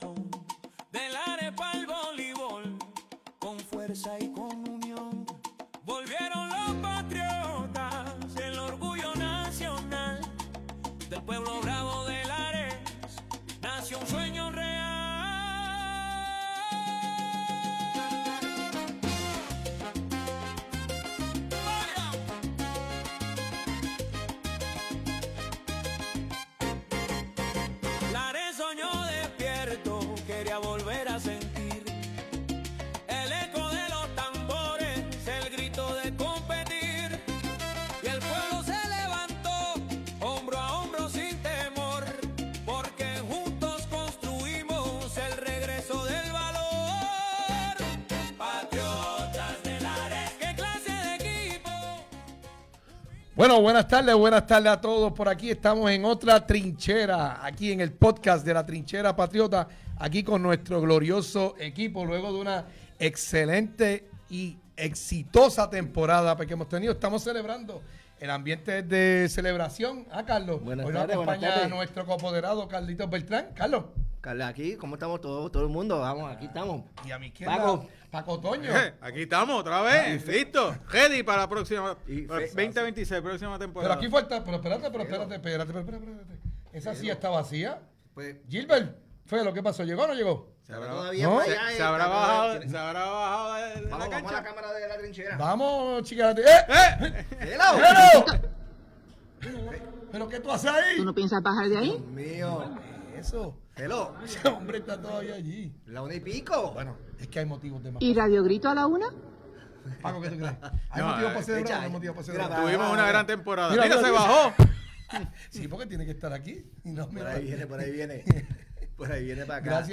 En La Trinchera Podcast estaremos compartiendo: ✅ Noticias y novedades de los Patriotas de Lares ✅ Calendario de juegos y próximos encuentros ✅ Entrevistas exclusivas con jugadores y personalidades